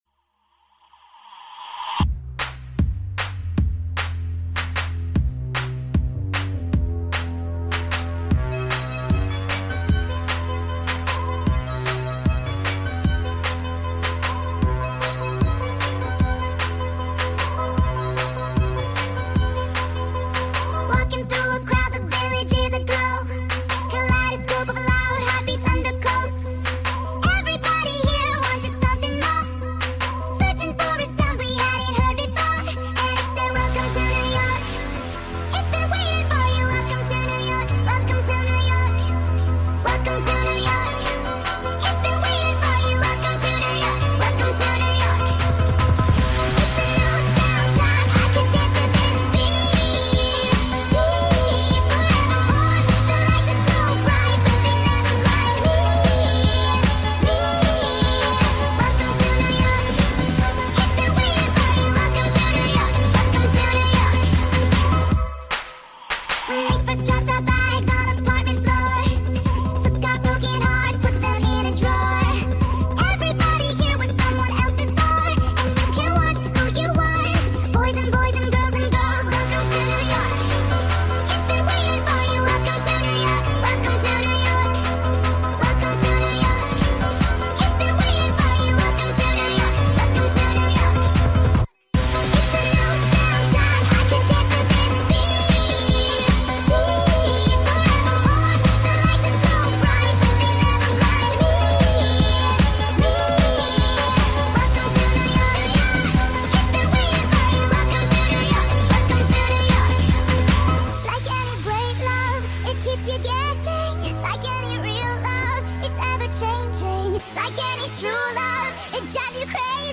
sped up full song